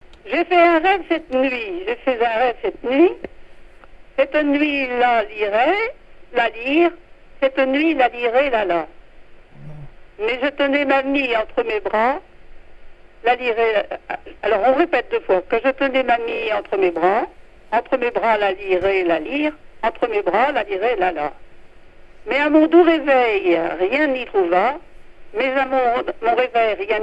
chansons traditionnelles
Pièce musicale inédite